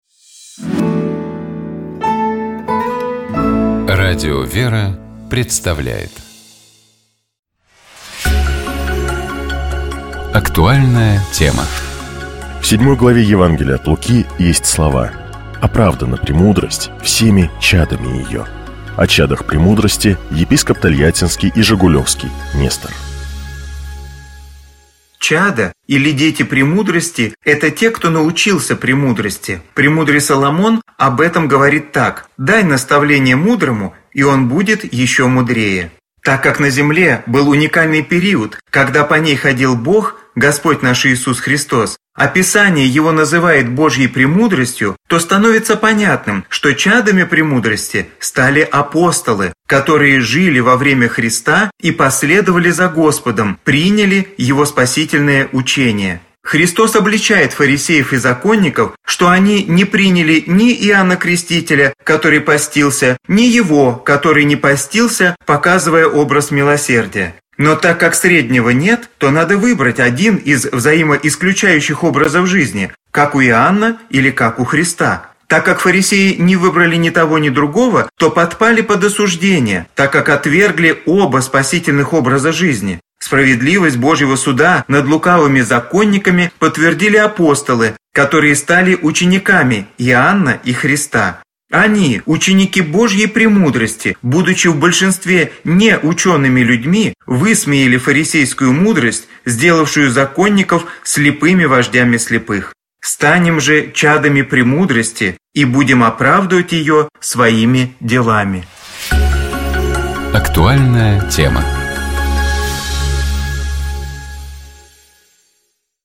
О чадах премудрости, — епископ Тольяттинский и Жигулёвский Нестор.